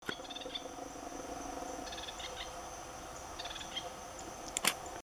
Pichororé (Synallaxis ruficapilla)
Nome em Inglês: Rufous-capped Spinetail
Fase da vida: Adulto
Localidade ou área protegida: Bio Reserva Karadya
Condição: Selvagem
Certeza: Gravado Vocal